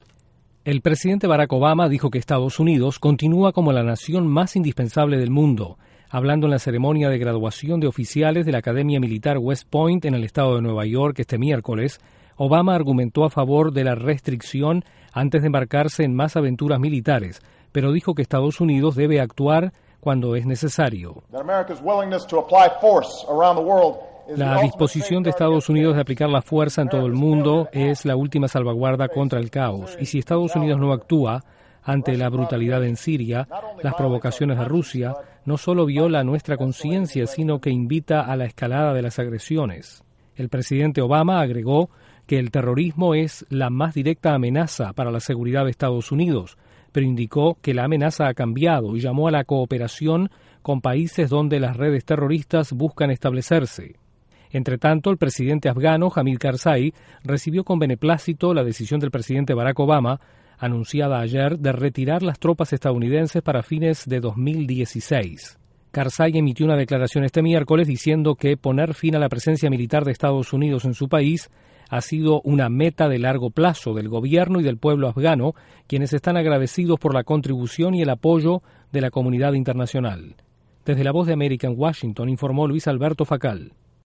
El terrorismo es la más directa amenaza que enfrenta Estados Unidos dijo el presidente Barack Obama ante una destacada academia militar. Desde la Voz de América en Washington